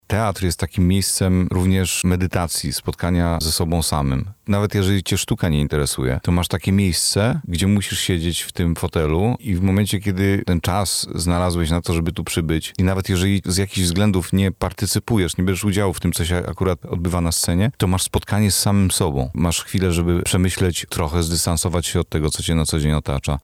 aktor i reżyser teatralny.